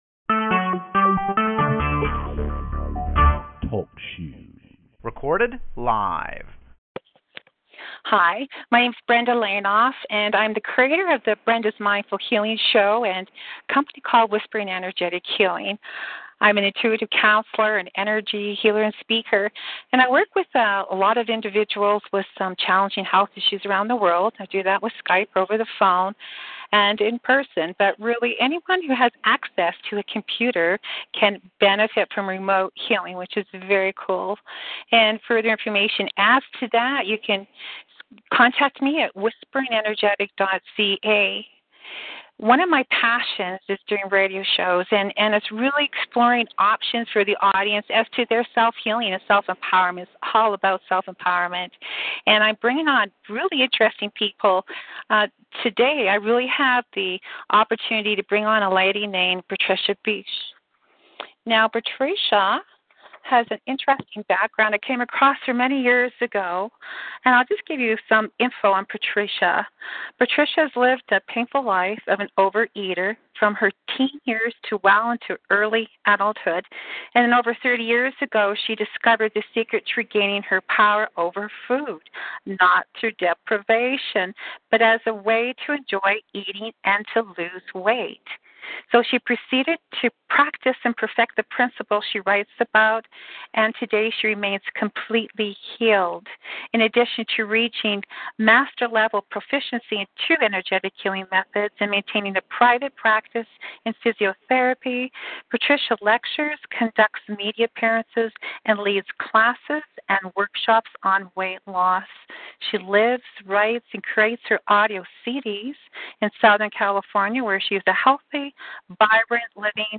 Talkshoe Episode 36 – Interview